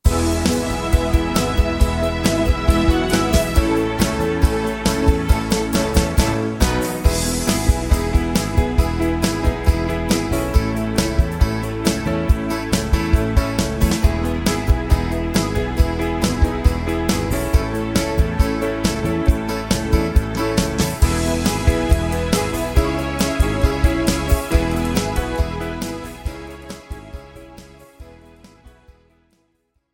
instrumental backing track cover
• Key – F
• Without Backing Vocals
• No Fade